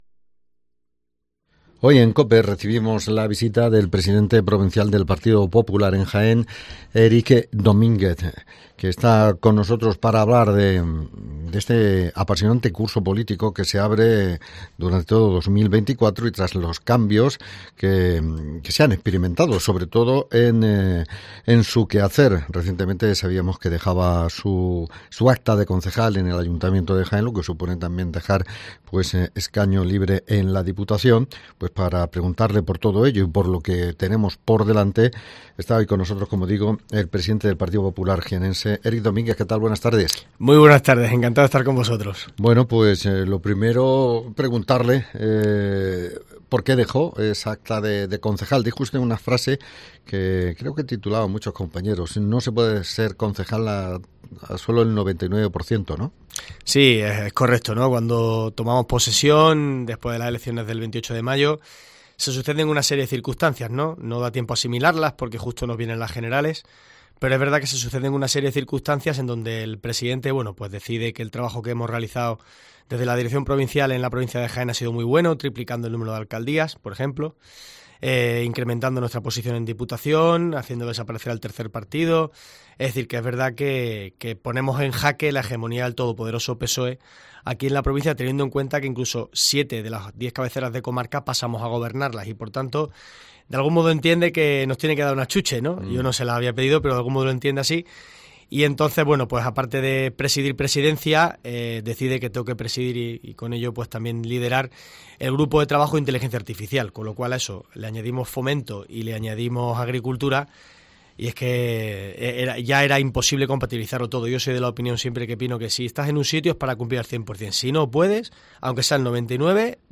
Hoy hablamos con el presidente del Partido Popular de Jaén que ha estado en los estudios de COPE.
Entrevista con Erik Domínguez